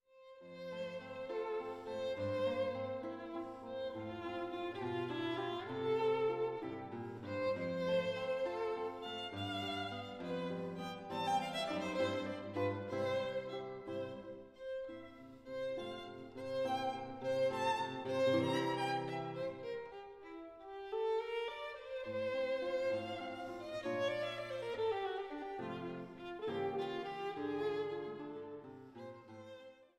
Violine
Gitarre
für Gitarre und Violine
Allegro moderato